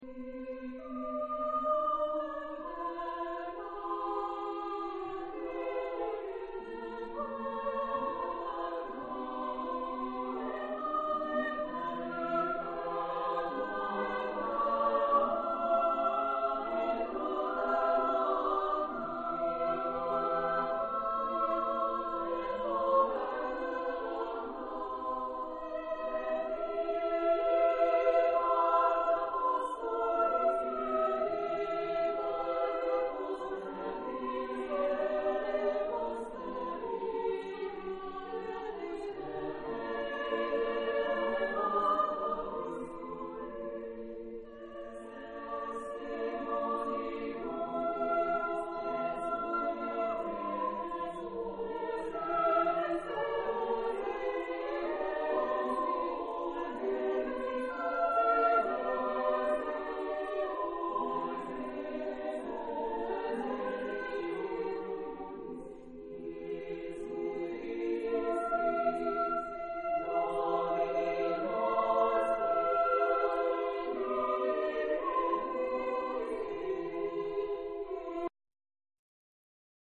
Genre-Style-Form: Sacred ; Renaissance
Type of Choir: SAATB  (5 mixed voices )
Discographic ref. : Internationaler Kammerchorwettbewerb Marktoberdorf